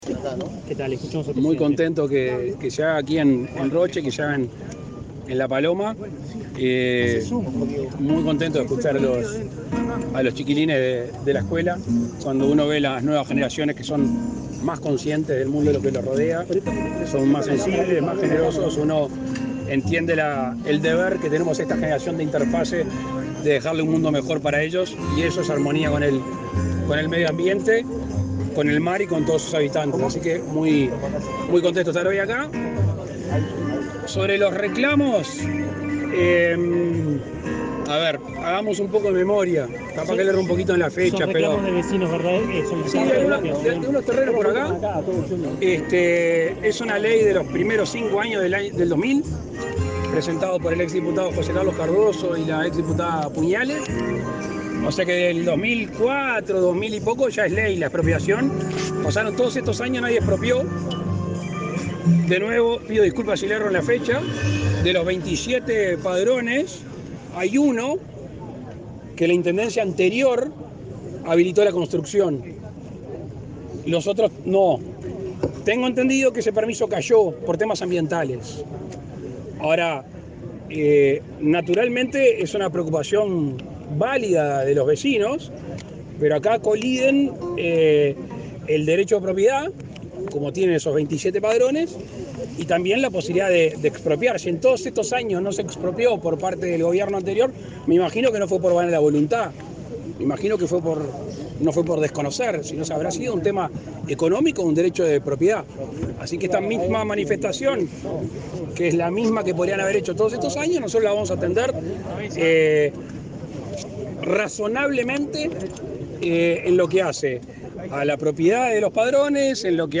Declaraciones del presidente Lacalle Pou a la prensa
Declaraciones del presidente Lacalle Pou a la prensa 29/08/2022 Compartir Facebook X Copiar enlace WhatsApp LinkedIn El presidente Luis Lacalle Pou encabezó el acto de lanzamiento de temporada de avistamiento de ballenas en el balneario La Paloma, departamento de Rocha. Luego, dialogó con la prensa.